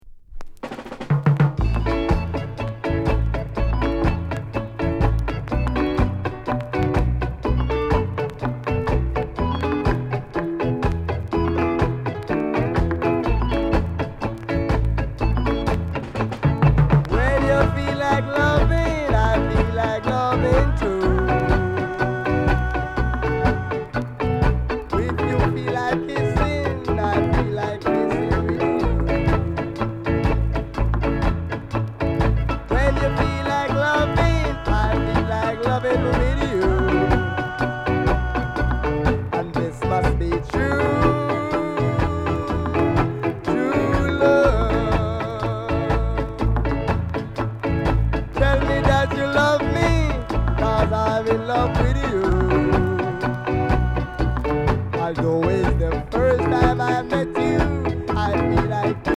EARLY REGGAE